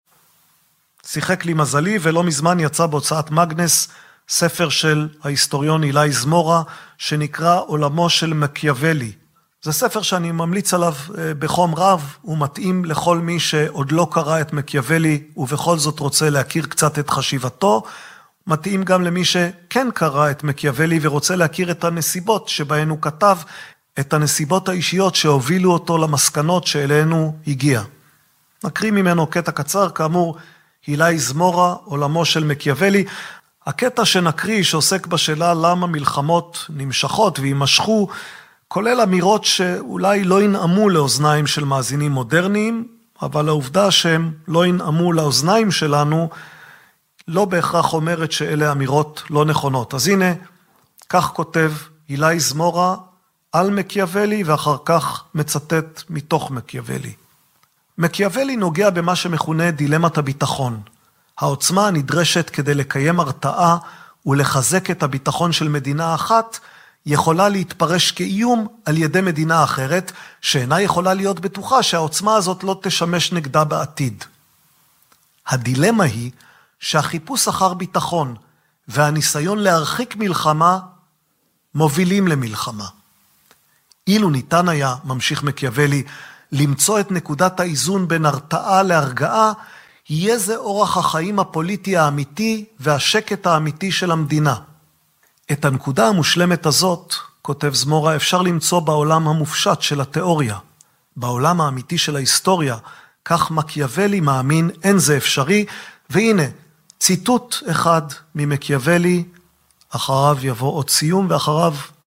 משוחח